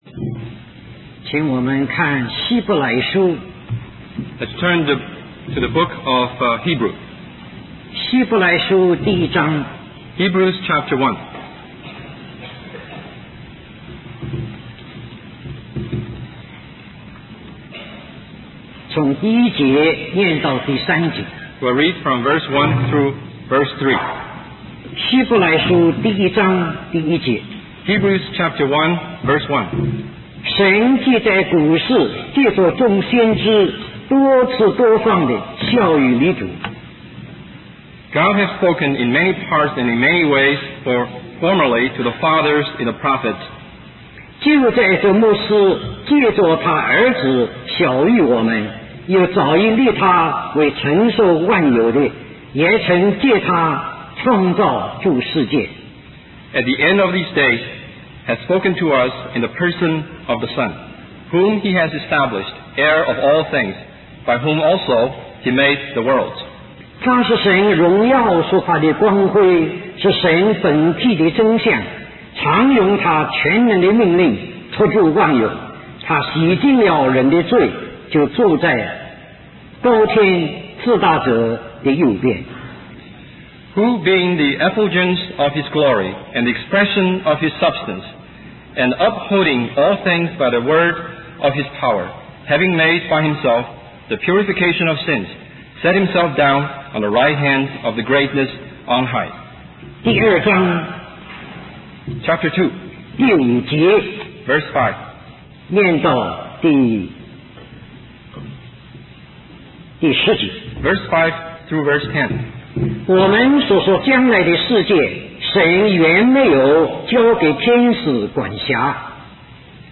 The sermon serves as a call to worship and a reminder of the majesty of Christ in the face of worldly distractions.